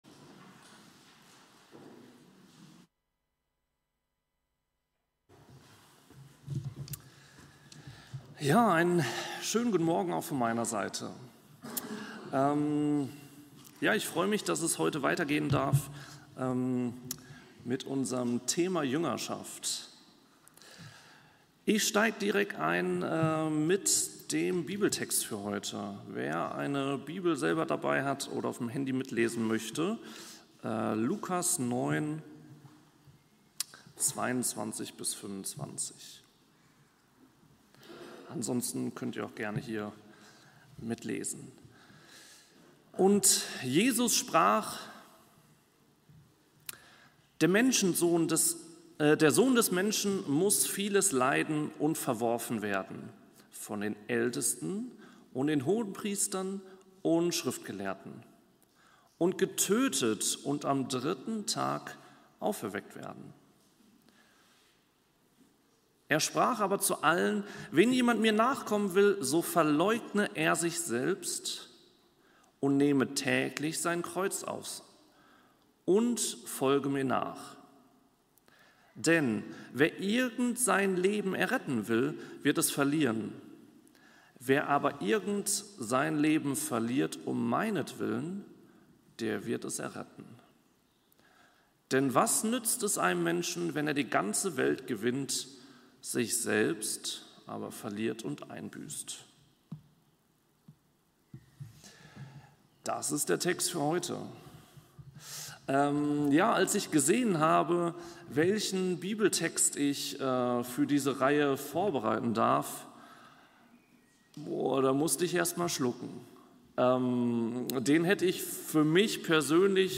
Predigt-am-04.05-online-audio-converter.com_.mp3